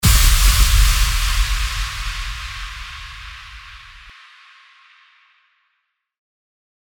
FX-617-WHOOSHY-IMPACT
FX-617-WHOOSHY-IMPACT.mp3